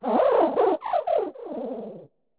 Cri de Pohmarmotte dans Pokémon Écarlate et Violet.